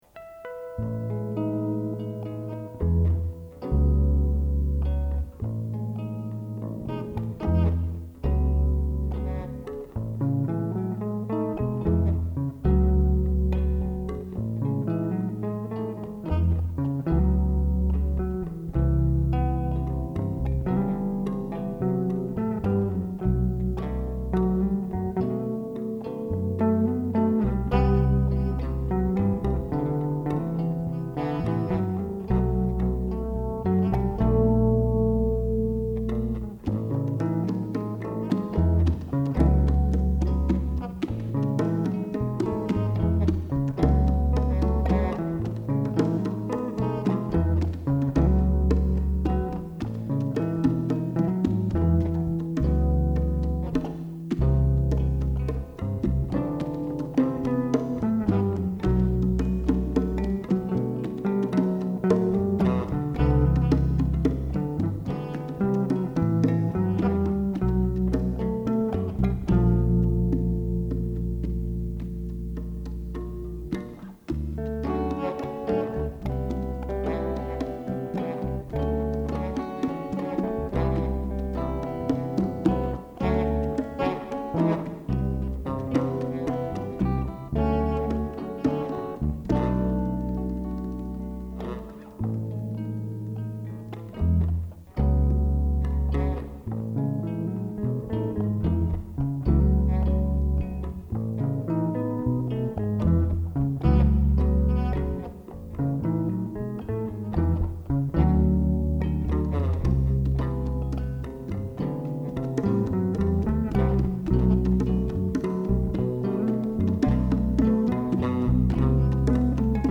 Production, mixing, all instruments by me.
Recorded on a 4-track at SUNY Purchase in 2003. Instrumentation note: This tune’s setup is two basses, guitar, and percussion.